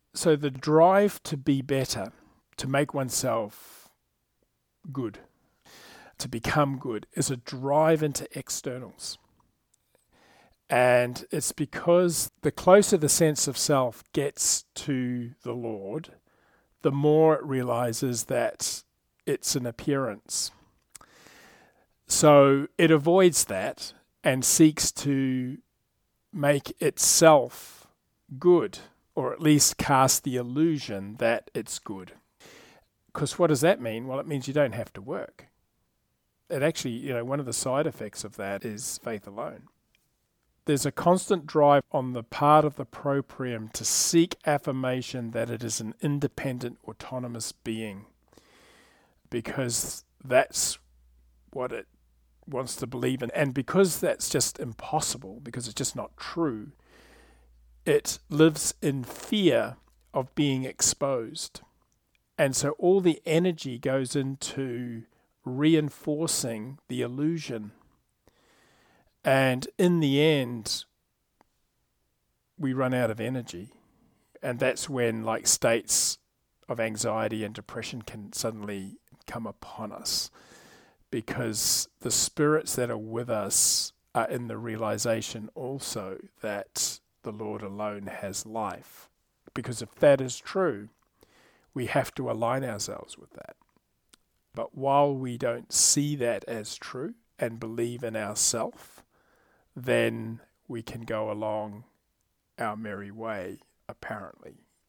Third Round posts are short audio clips taken from Round 3 comments offered in the online Logopraxis Life Group meetings. The aim is to keep the focus on understanding the Text in terms of its application to the inner life along with reinforcing any key LP principles that have been highlighted in the exchanges.